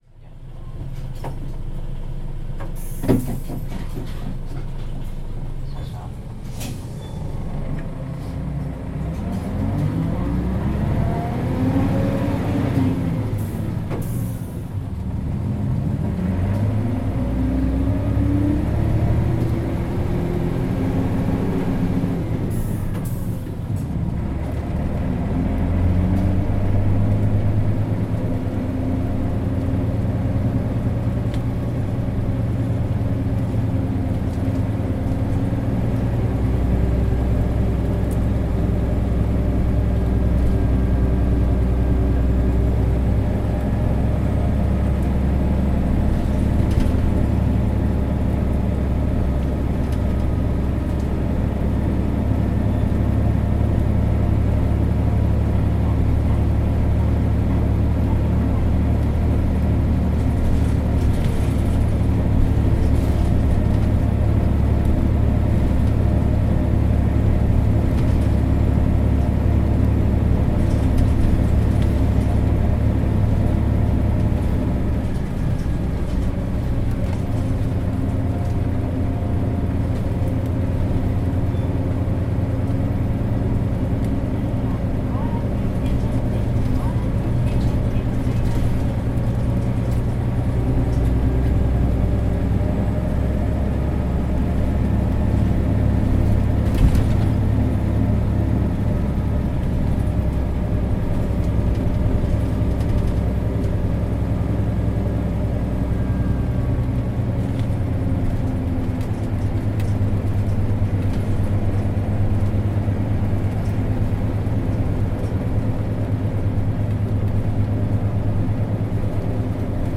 全国路線バス走行音東海バス(日野車)